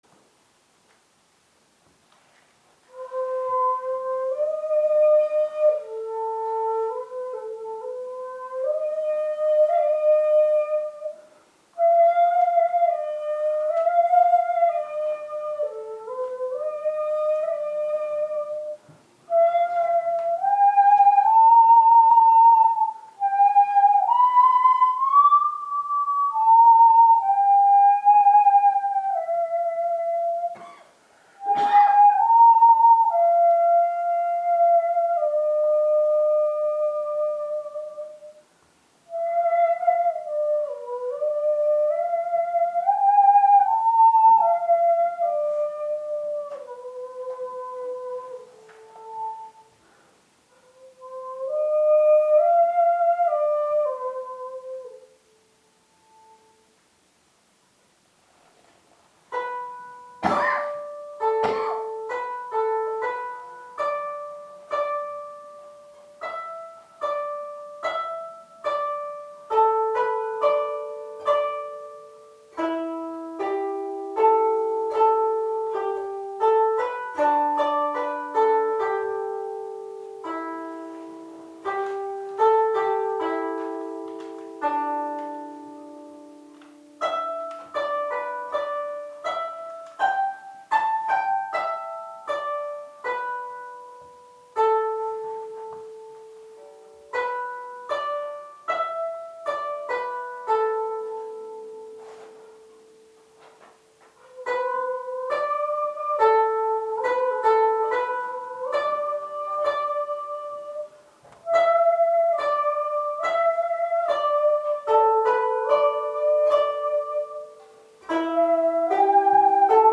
第2部　一節切〜尺八・琴の二重奏
３．「吉野の山」（江戸前期の流行歌復元演奏）
今から350年程前の曲と言いますと狭い音域で謡(うたい）のようなものだと思いがちですが、B３からD4までの1オクターブ以上の旋律の動きがあります。